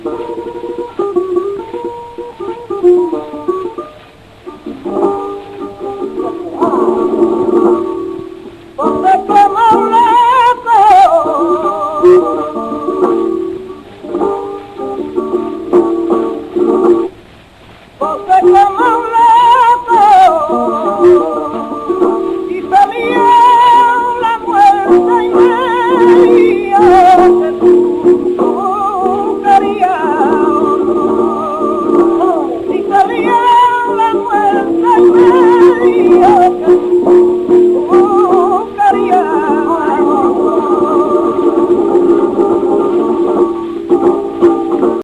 Manuel Cagancho - Manuel Pavón / Ramón Montoya
Soleá de Manuel Cagancho